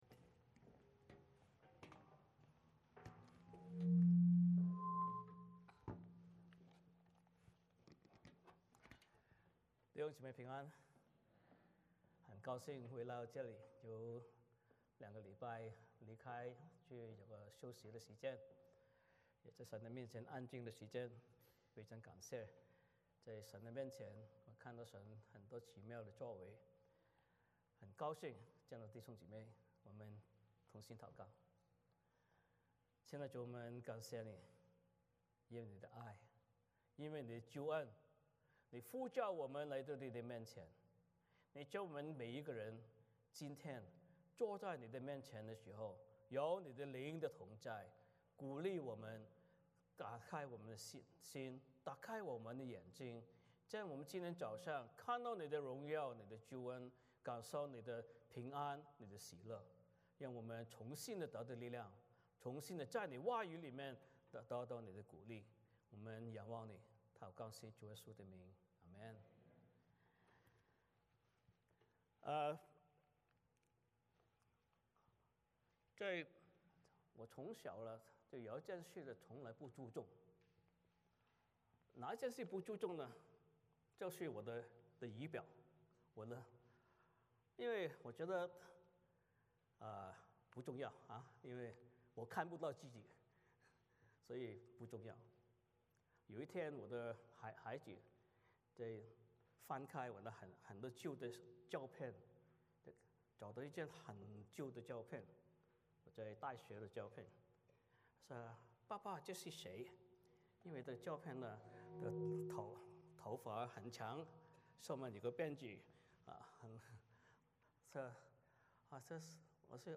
Passage: 帖撒罗尼迦前书 4:1-12 Service Type: 主日崇拜 欢迎大家加入我们的敬拜。